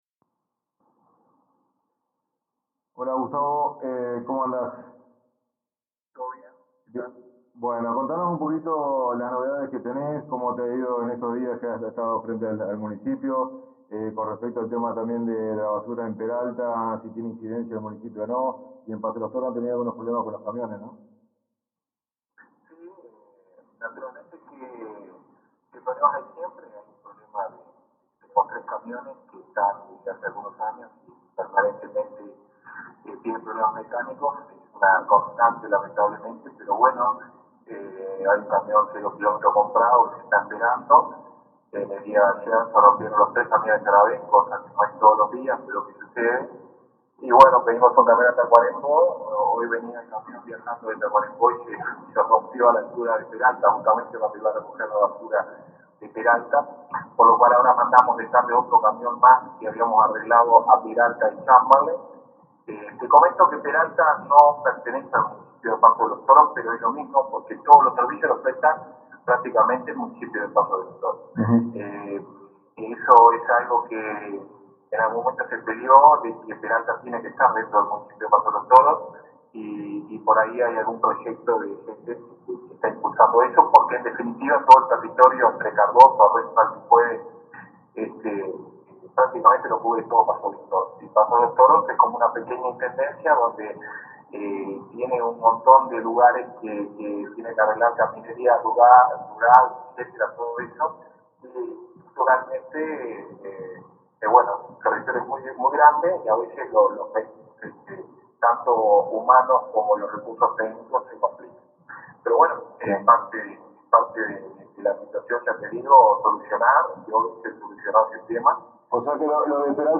En la jornada de hoy conversamos con el alcalde interino del Municipio de Paso de los Toros, Gustavo Amarillo, quien expresó cómo han transcurrido estos días frente al Municipio y contó novedades.